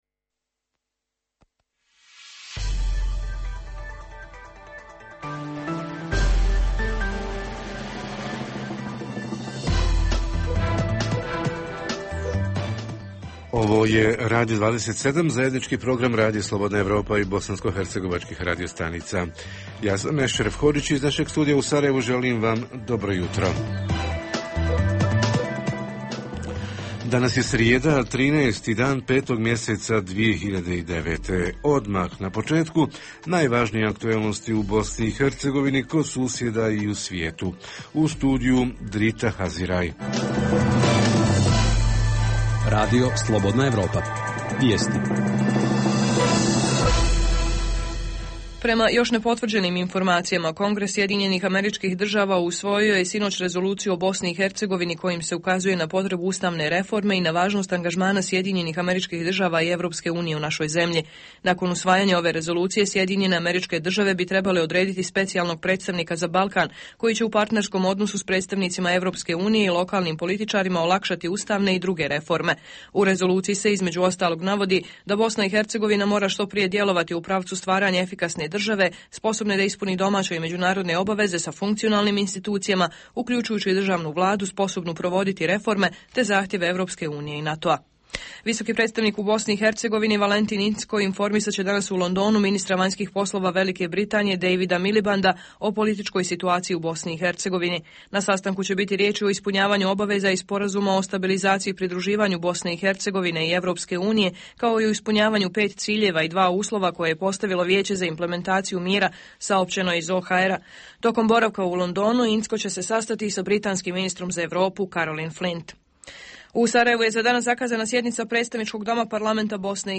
Jutarnji program pita kako i koliko lokalna uprava podstiče osnivanje i razvoj malih i srednjih preduzeća? Reporteri iz cijele BiH javljaju o najaktuelnijim događajima u njihovim sredinama.